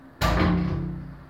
描述：一只手拍打玻璃
Tag: 湿 耳光 玻璃